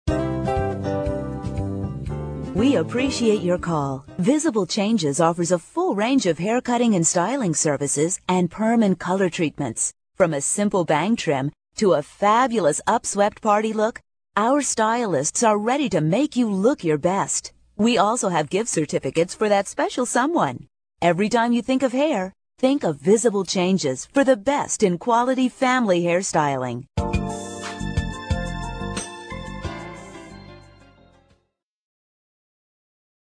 Audio Demos - Message On Hold